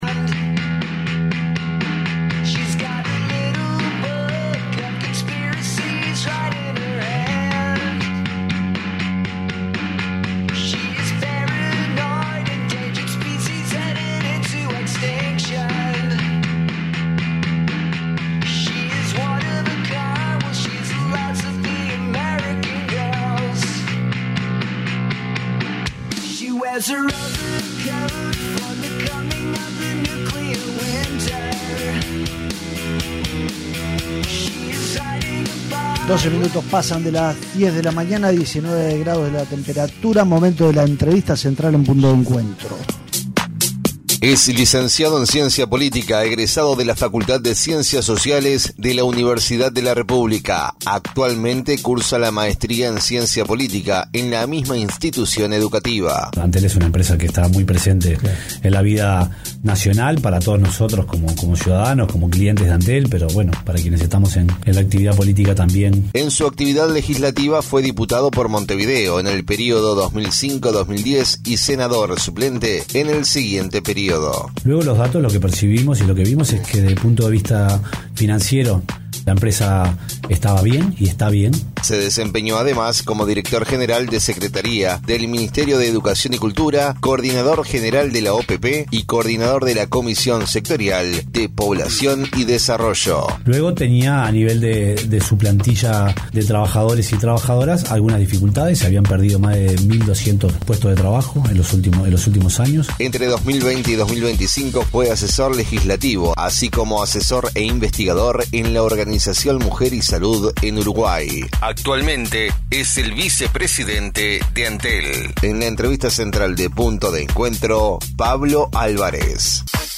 ENTREVISTA: PABLO ÁLVAREZ